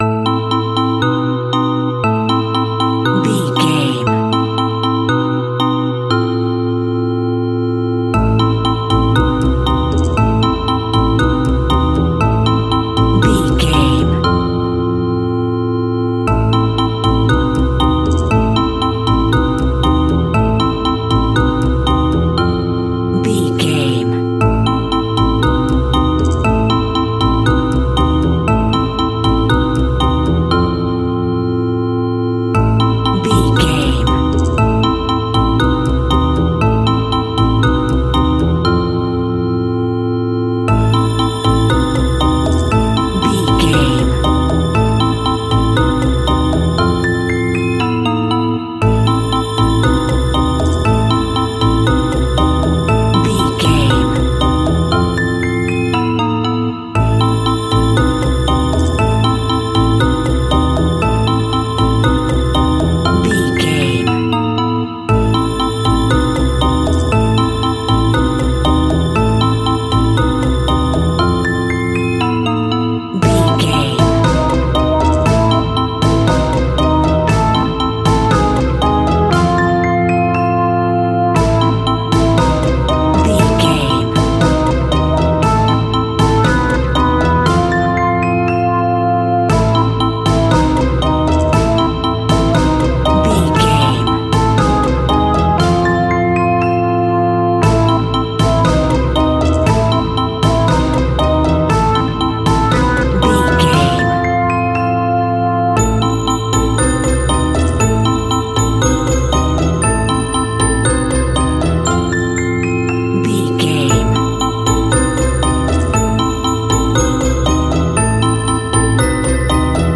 Aeolian/Minor
scary
ominous
dark
eerie
playful
bouncy
percussion
double bass
piano
strings
mysterious
spooky